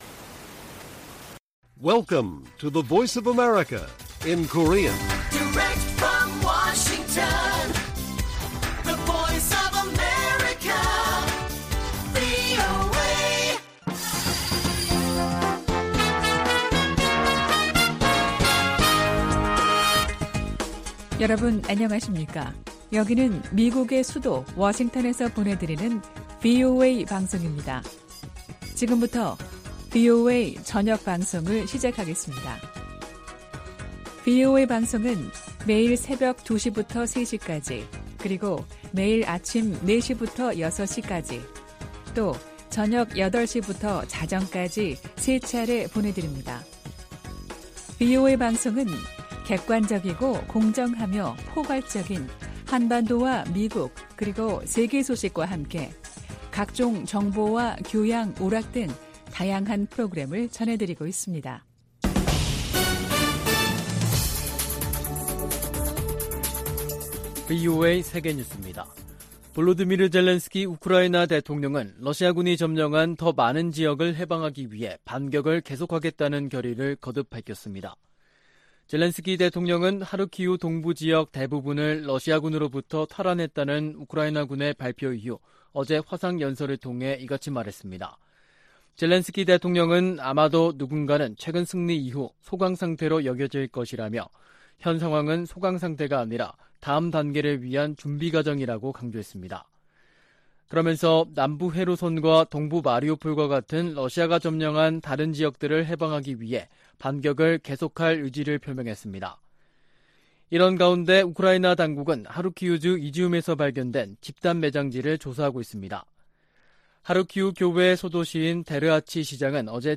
VOA 한국어 간판 뉴스 프로그램 '뉴스 투데이', 2022년 9월 19일 1부 방송입니다. 미국은 대북 억제를 위해 전략자산의 효과적인 역내 전개와 운용이 지속되도록 한국과의 공조 강화를 약속했습니다. 미 해군은 로널드 레이건 항공모함이 부산에 입항해 한국군과 연합훈련할 계획이라고 밝혔습니다. 제77차 유엔총회에서 미국은 식량 안보와 보건 협력, 안보리 개혁 문제를 주요 우선순위로 다룹니다.